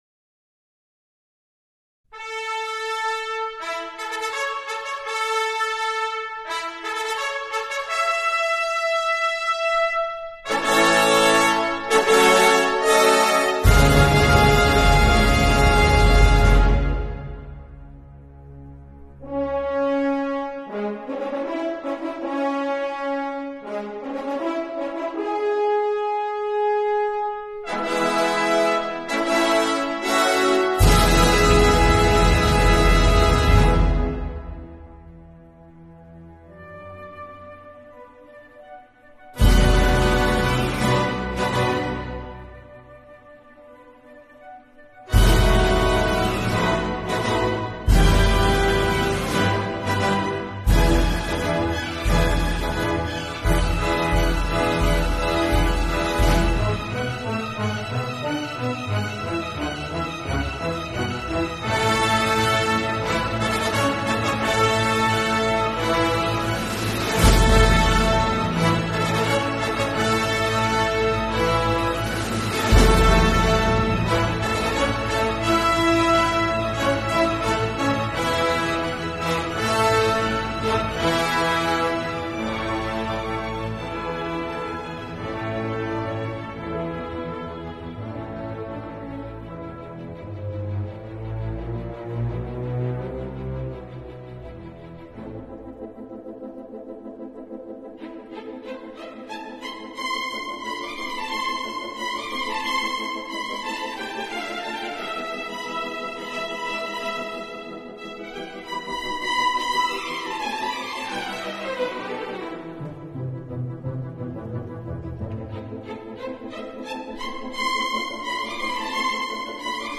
about Music – Sundowner Programme 14 This week we will enjoy a programme of “Sundowner” music. Easy listening for the end of the day.